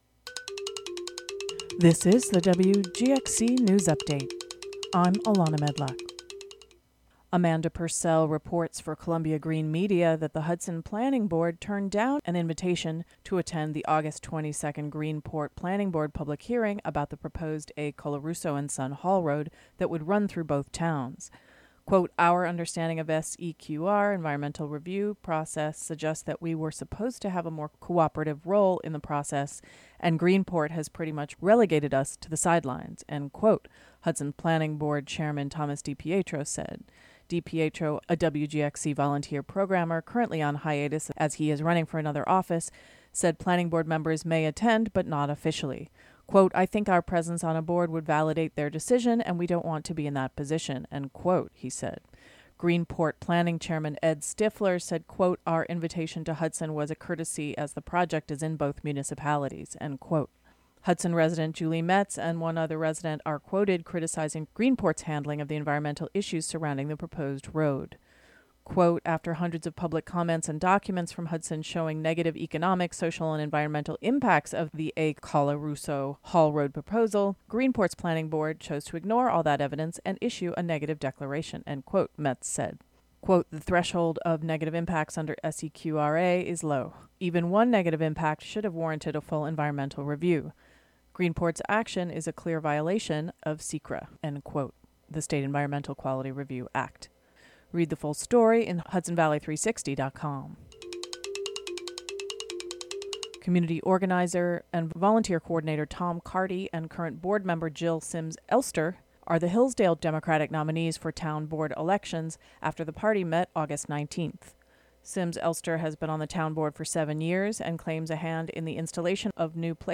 DOWNLOAD or play the audio version of the local news update for Tuesday, August 22 (3:21).